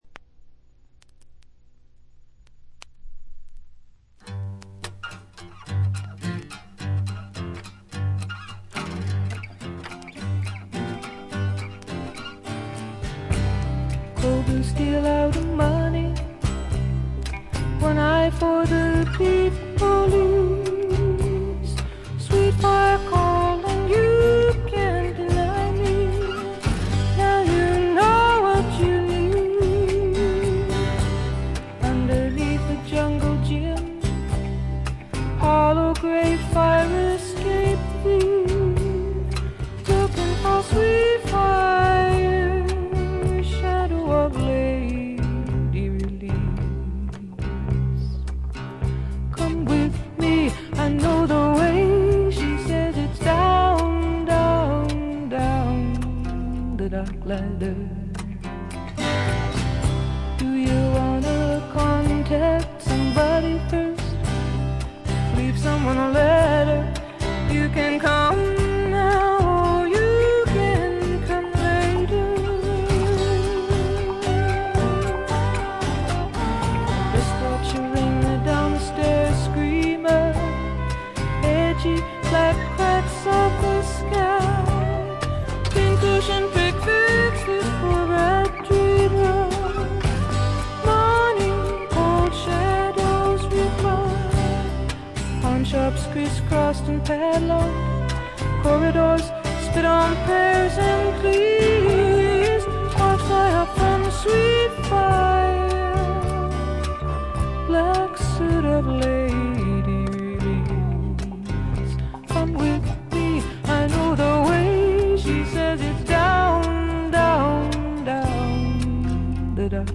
女性シンガーソングライター基本盤。
試聴曲は現品からの取り込み音源です。
Electric Guitar